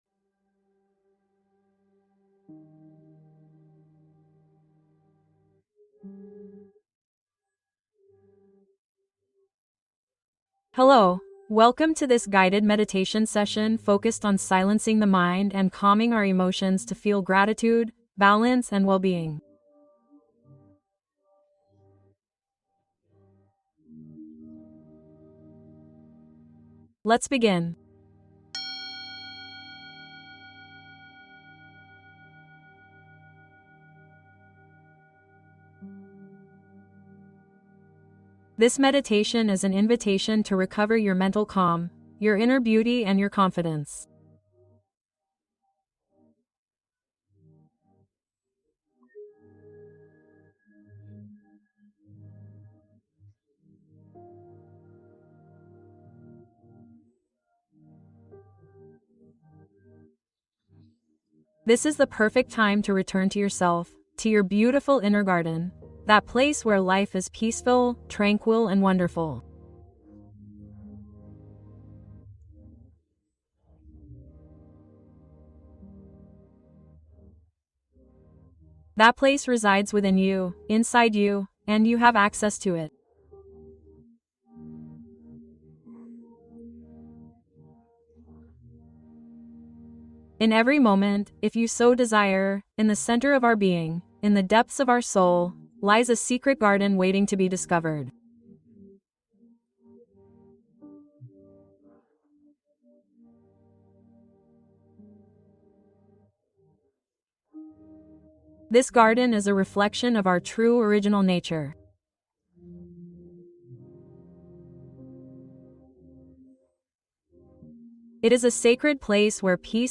Dormir Feliz y Sanar Dolor de Cuerpo y Mente | Meditación Profunda